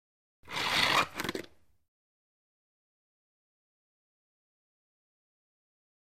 Звуки скейтборда
Скейтбордист выполняет трюки